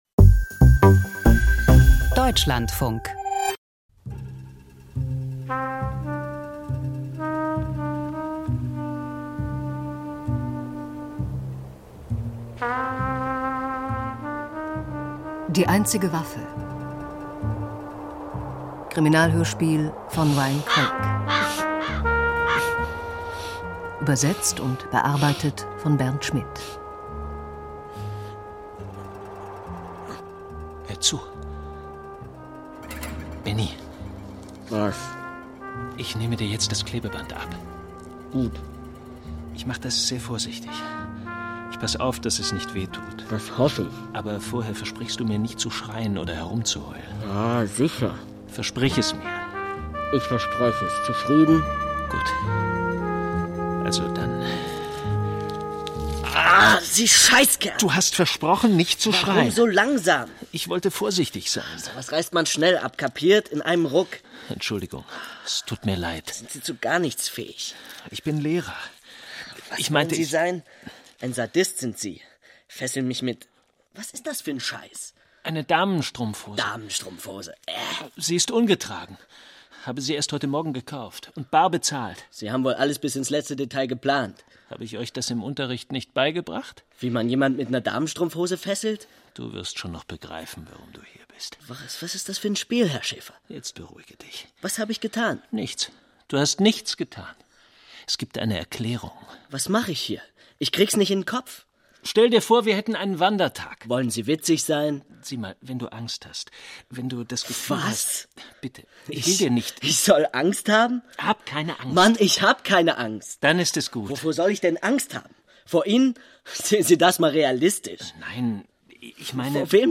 Email Audio herunterladen Es brutzelt und zischt in der Studioküche.